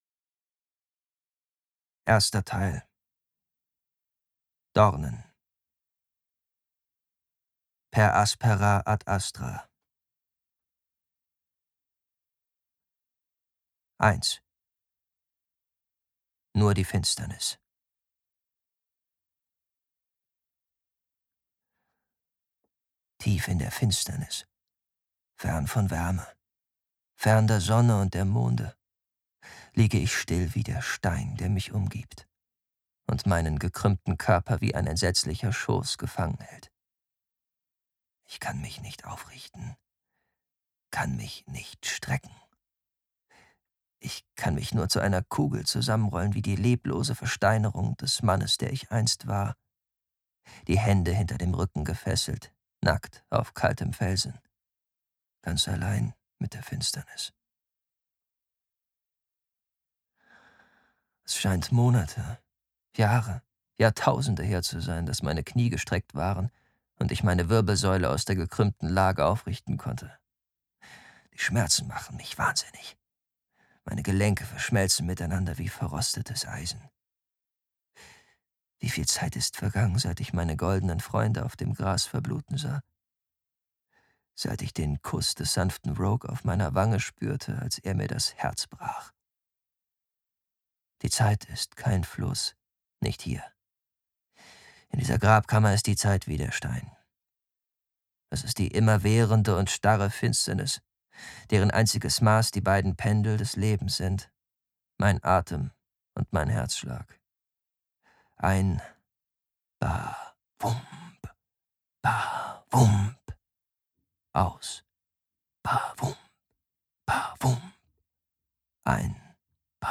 Hörproben: Die Red Rising Trilogie